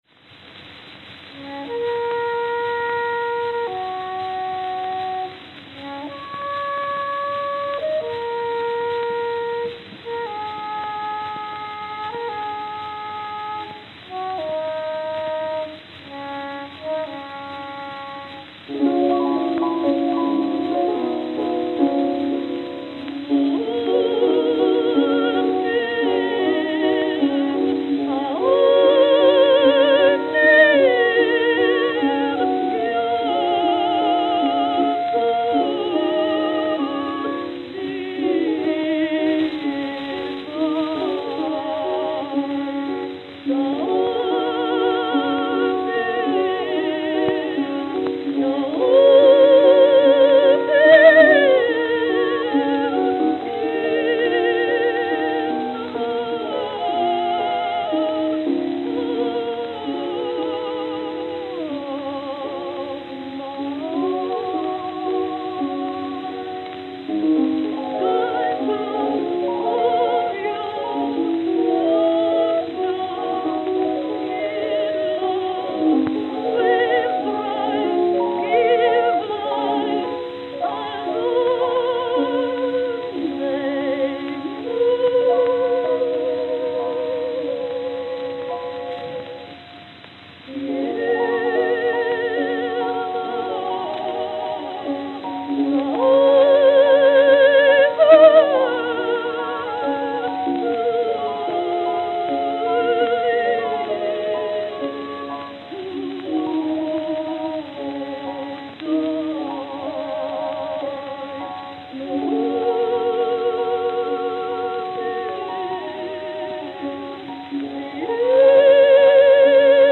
Victor 10-Inch Double-Sided Acoustical Records